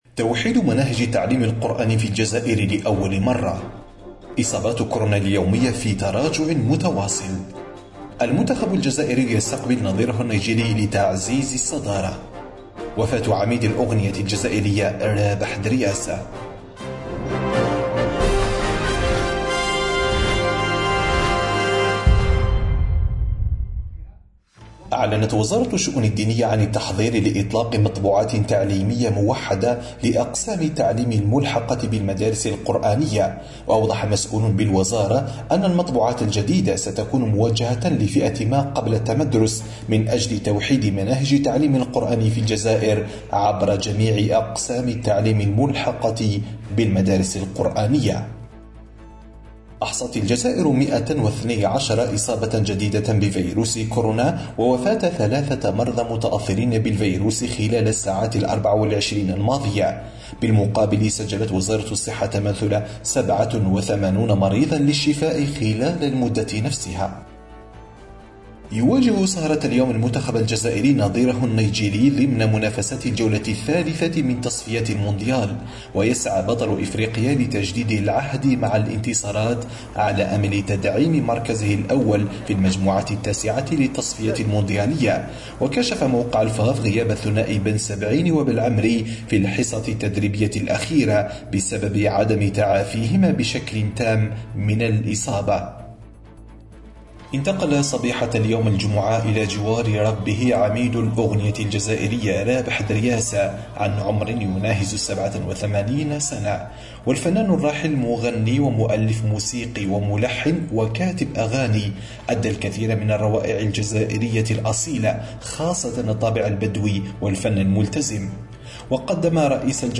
النشرة اليومية: لأول مرة.. توحيد مناهج التعليم القرآني – أوراس